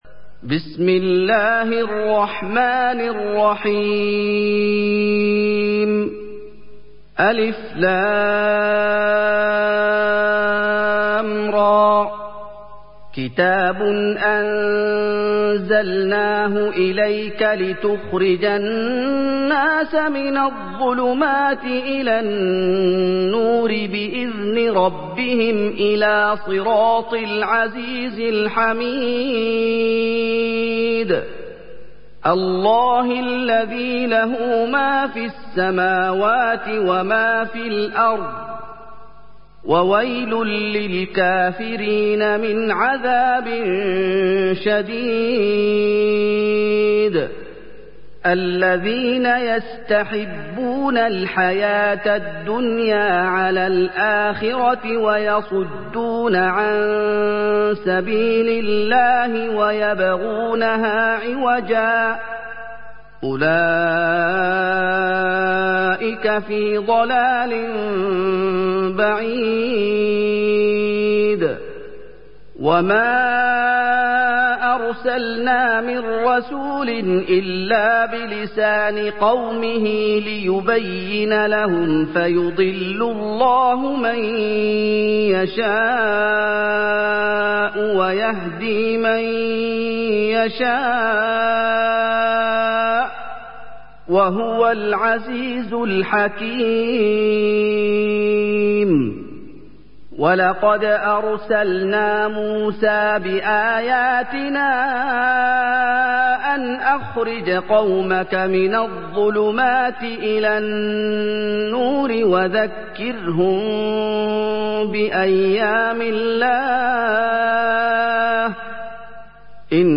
سُورَةُ ابراهيم بصوت الشيخ محمد ايوب